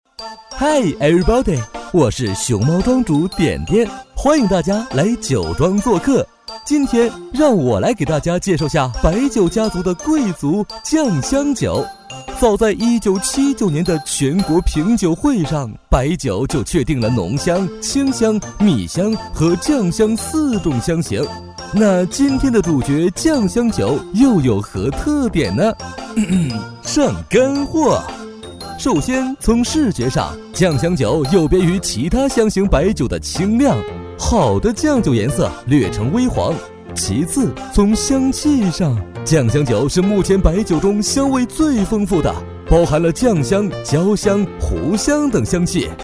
MG动画男119号（活泼）
轻松自然 MG动画